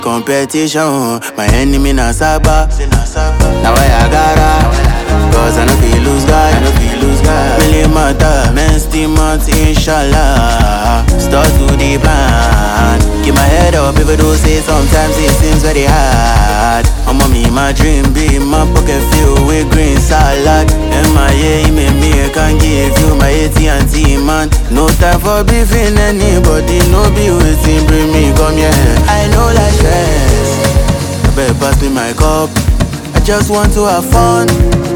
Afrobeats, African